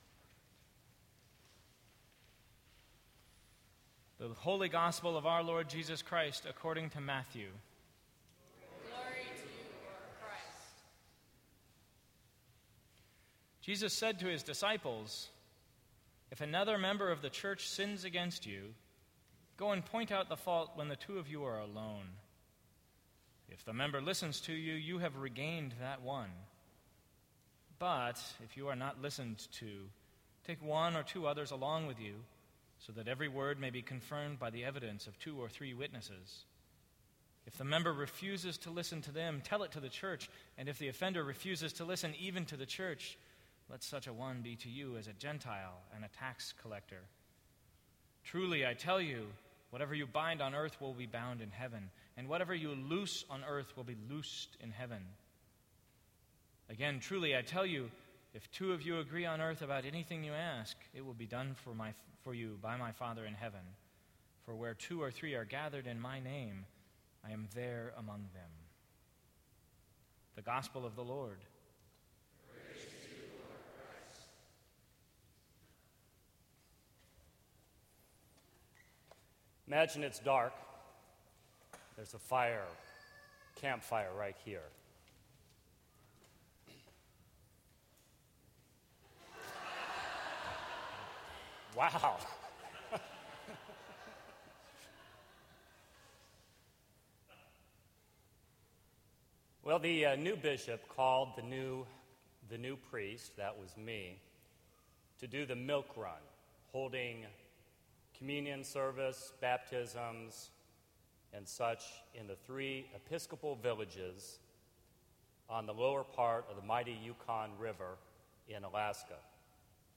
Sermons from St. Cross Episcopal Church September 7, 2014.